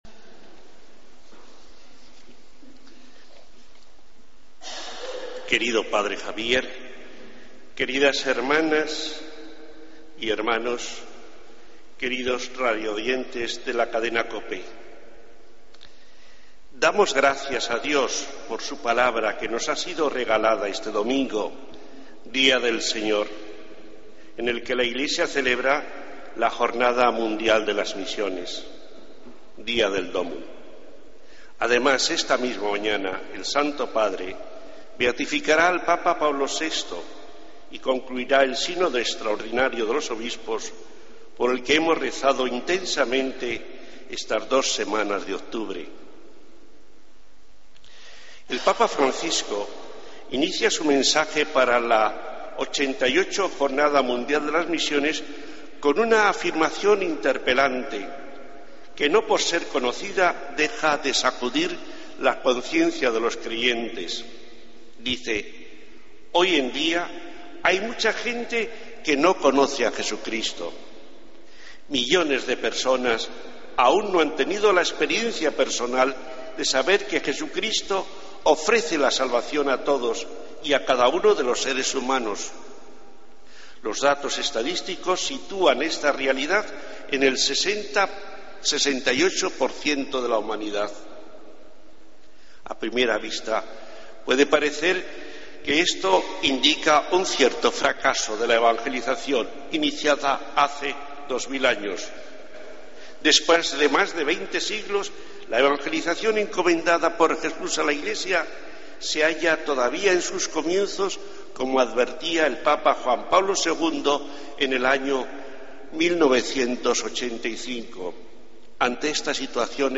Homilía del Domingo 19 de Octubre de 2014